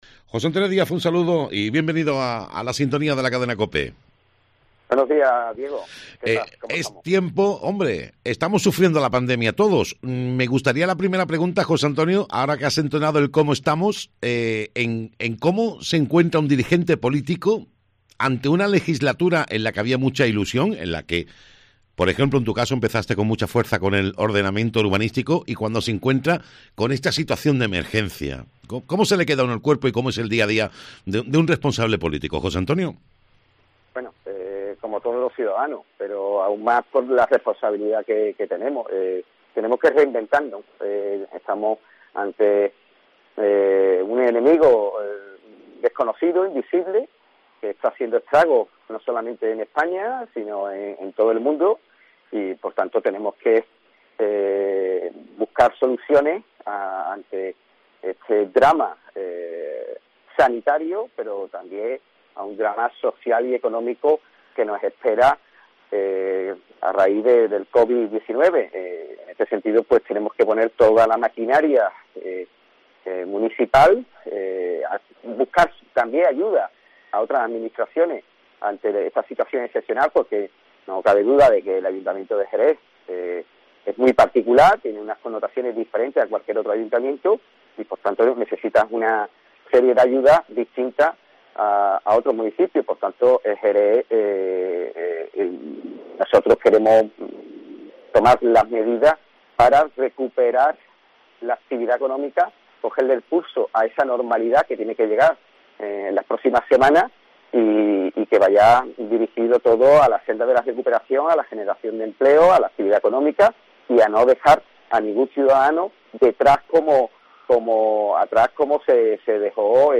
Entrevista en COPE a José Antonio Díaz, segundo teniente de alcaldesa del Ayuntamiento de Jerez